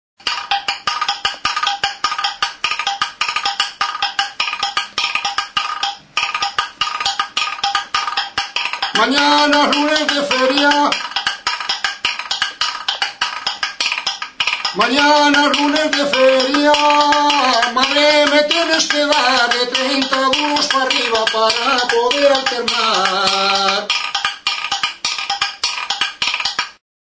EUROPE -> KANTABRIA
RASCADORES
Idiophones -> Scraped
2 behi adar dira.